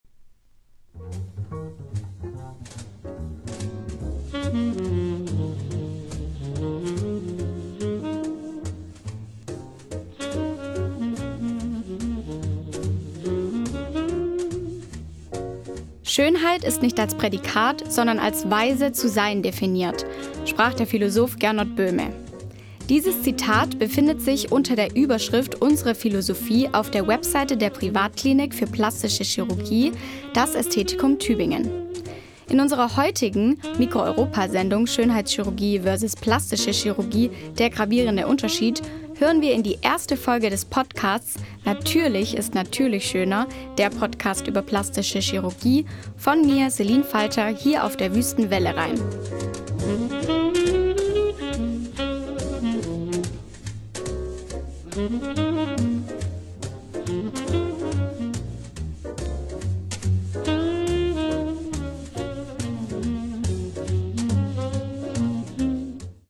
Beitrag
Musik: